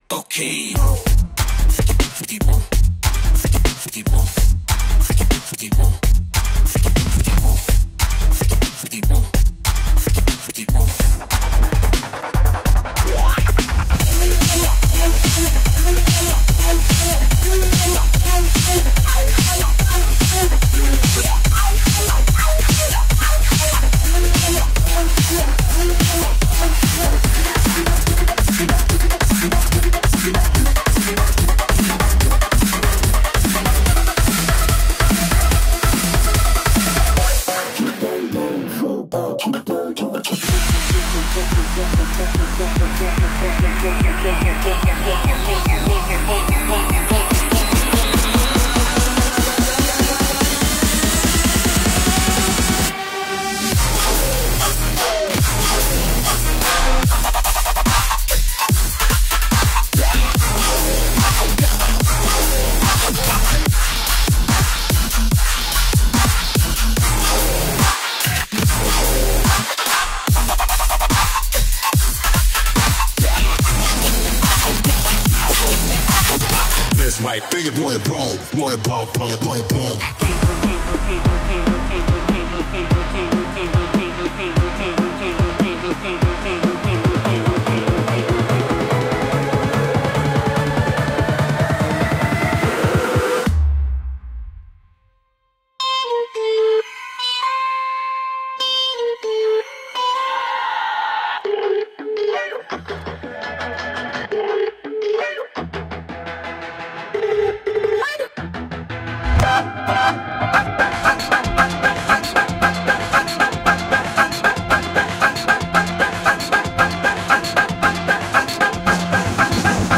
It starts off enough like an opera for my taste, but it quickly devolves into a kind of 2000's pop musical style.
Comic operetta, Gilbert & Sullivan style, orchestral, Victorian parlor music